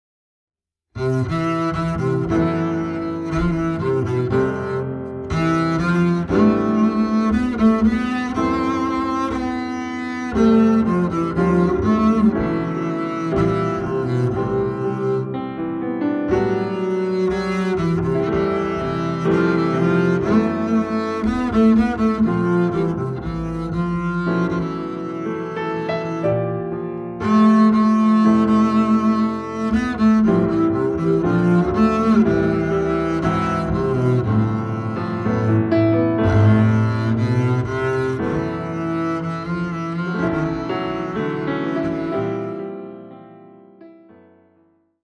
Piano
Bass
レコーディングは、山中湖から少し上がった山間のスタジオ。